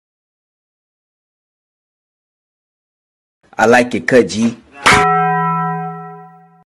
I crash into a wall. sound effects free download